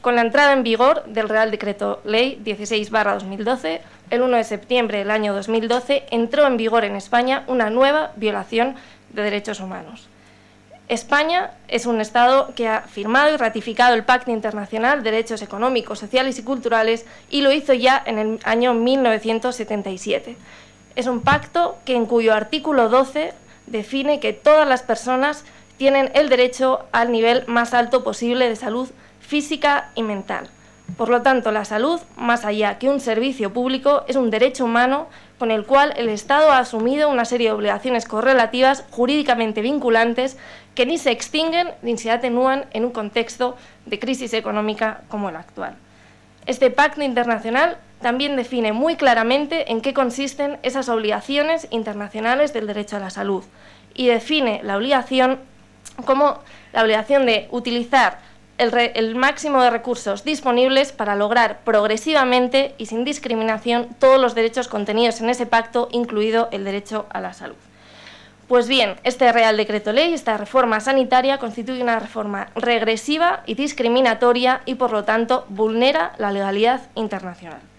Un año de recortes en la sanidad pública. Congreso de los Diputados. 11/10/2013